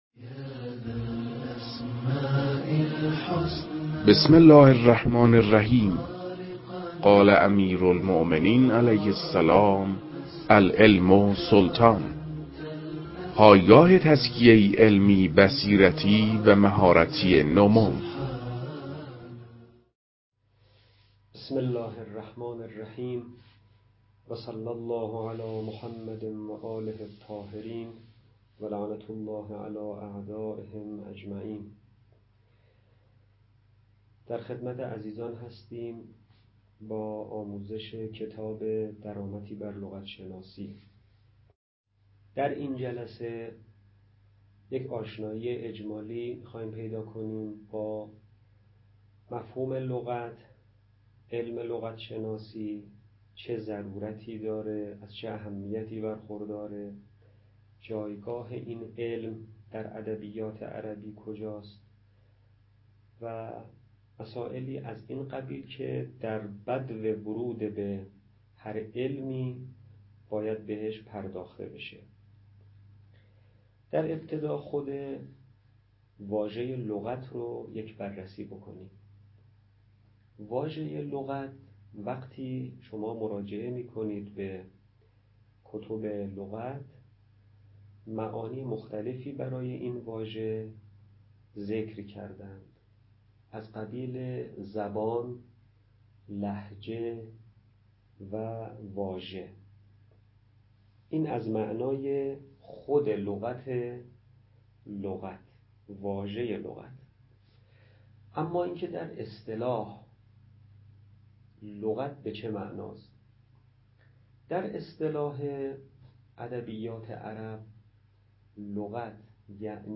در این بخش، کتاب «درآمدی بر لغت‌شناسی» که اولین کتاب در مرحلۀ آشنایی با علم لغت است، به صورت ترتیب مباحث کتاب، تدریس می‌شود.
در تدریس این کتاب- با توجه به سطح آشنایی کتاب- سعی شده است، مطالب به صورت روان و در حد آشنایی ارائه شود.